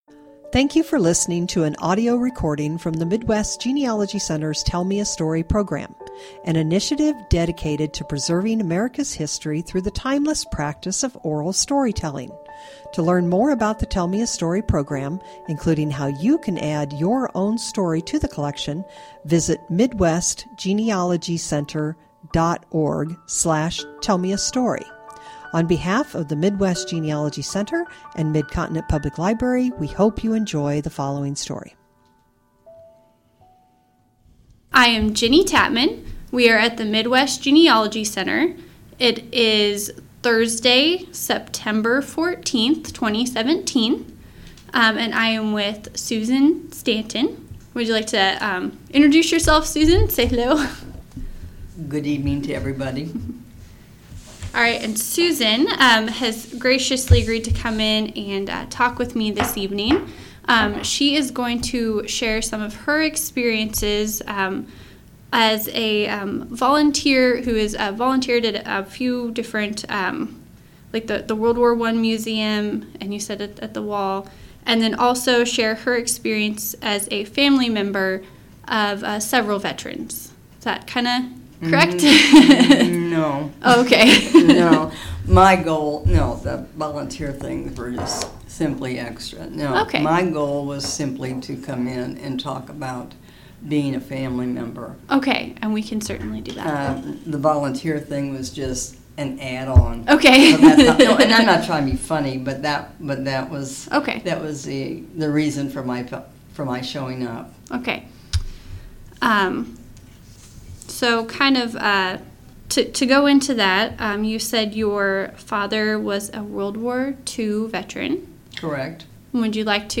Oral Interview
Oral History